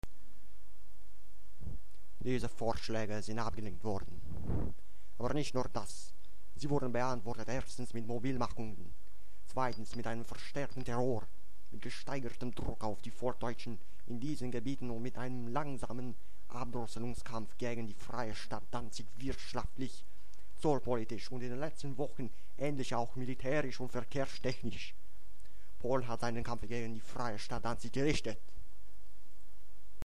Non-native speaker